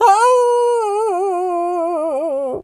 pgs/Assets/Audio/Animal_Impersonations/wolf_hurt_06.wav at master
wolf_hurt_06.wav